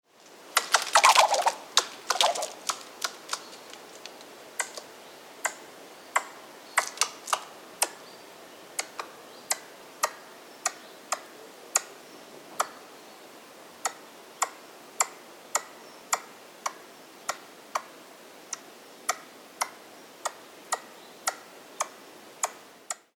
Genre: Animal Sound Recording.
2500_Sciurus_vulgaris_call_short.mp3